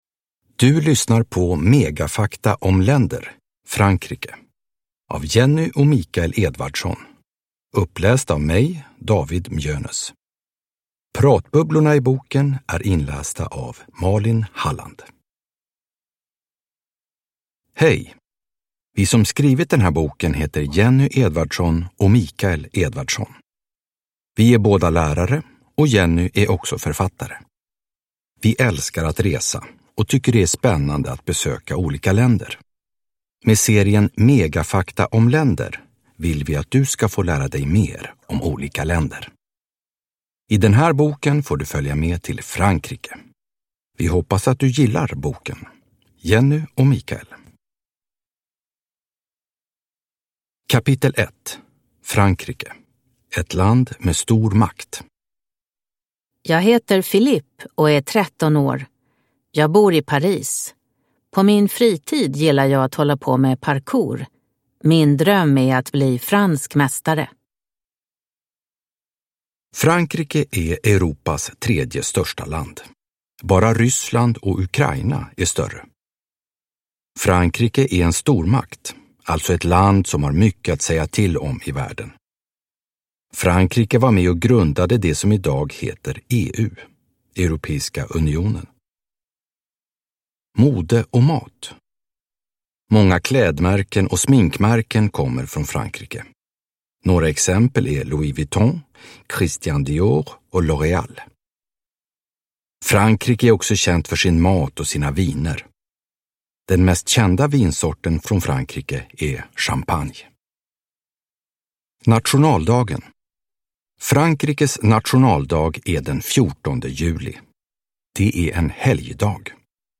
Megafakta om länder. Frankrike (ljudbok